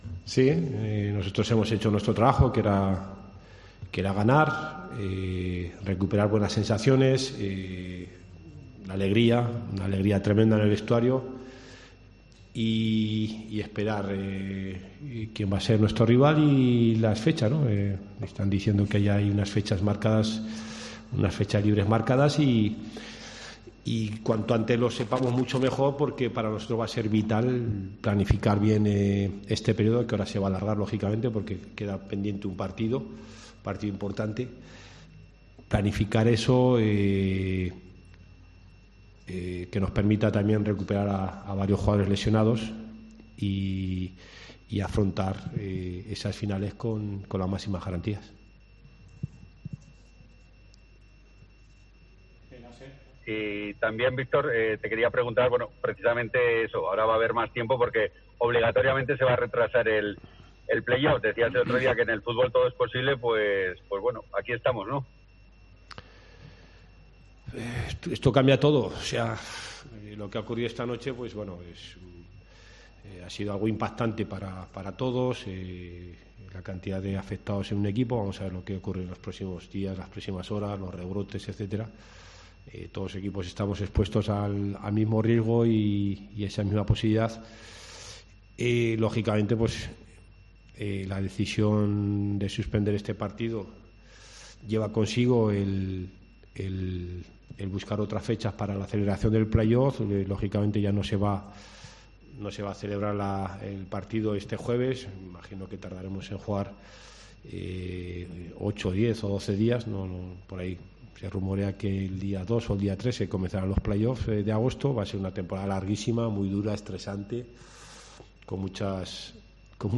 AUDIO: Escucha aquí las palabras del entrenador del Real Zaragoza tras la victoria en La Romareda 2-1 ante la Deportiva Ponferradina